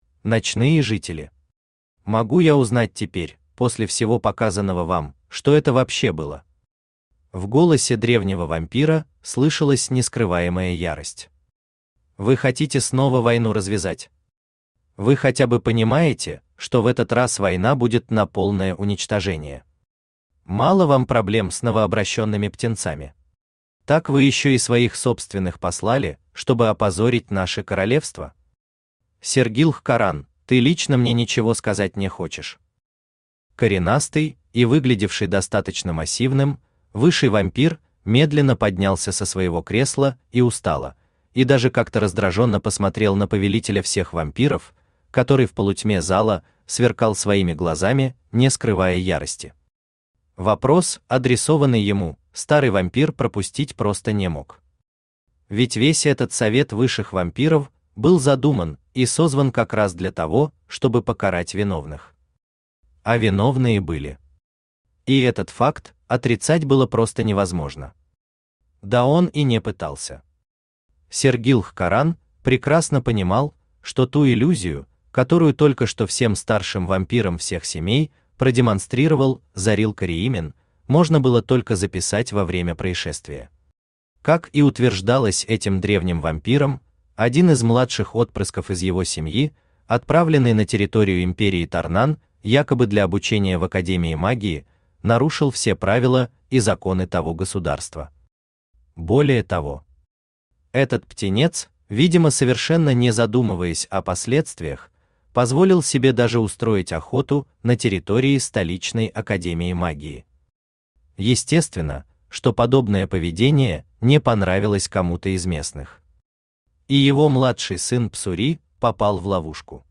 Аудиокнига Лесовик. Кто-то во Тьме | Библиотека аудиокниг
Кто-то во Тьме Автор Хайдарали Усманов Читает аудиокнигу Авточтец ЛитРес.